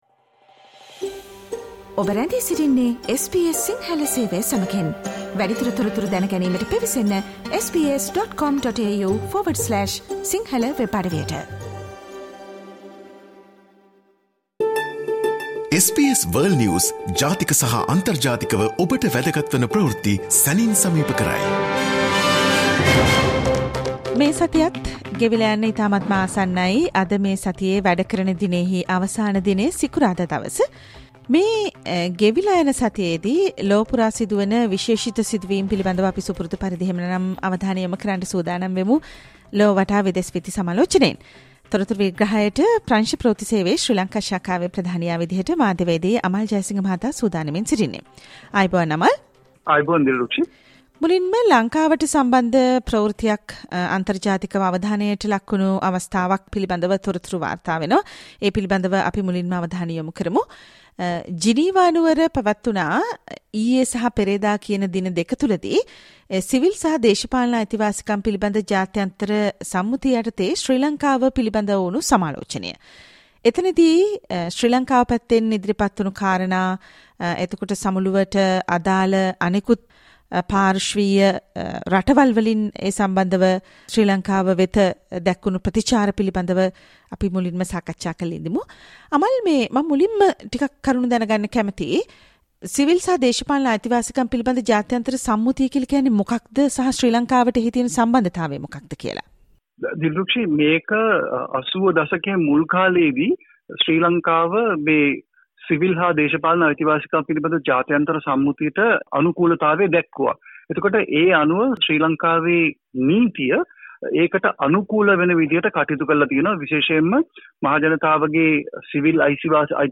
World's prominent news highlights in 18 minutes - listen to the SBS Sinhala Radio's weekly world News wrap on every Friday.